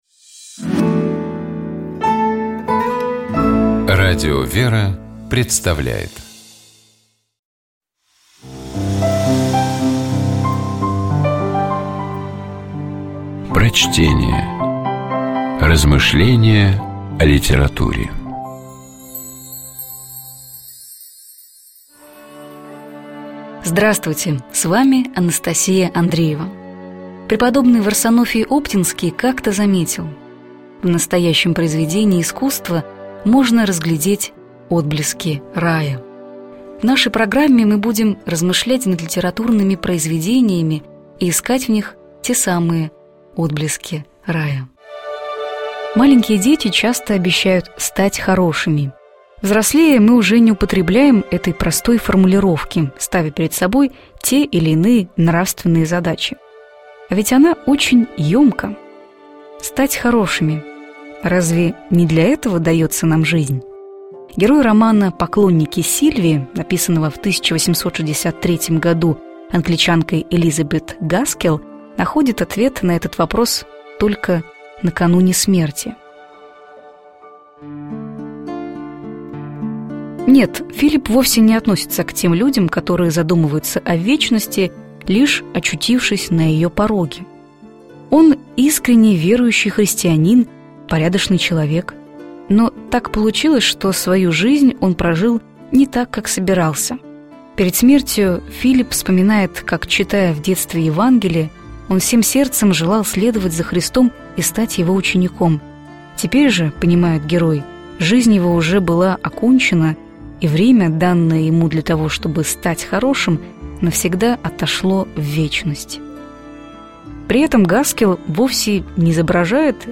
Prochtenie-Je_-Gaskell-_Poklonniki-Silvii-Stat-horoshim.mp3